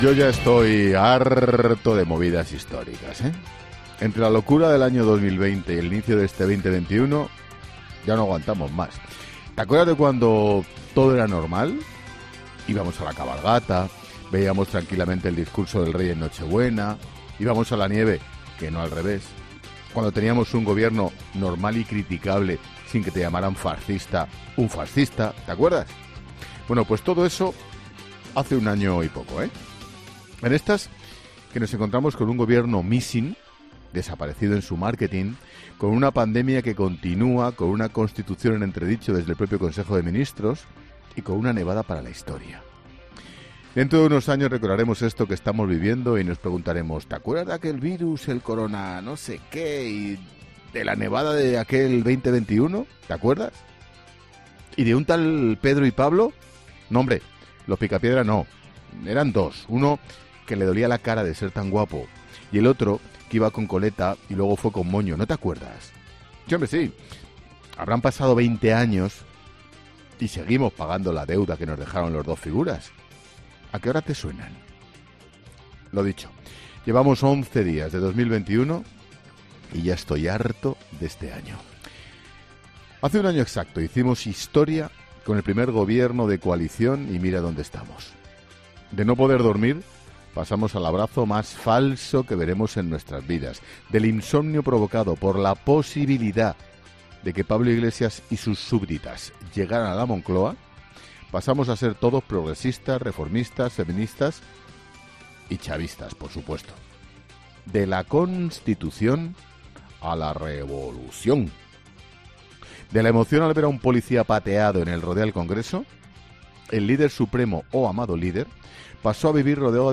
Monólogo de Expósito
El director de 'La Linterna', Ángel Expósito, analiza las principales noticias de este recién estrenado 2021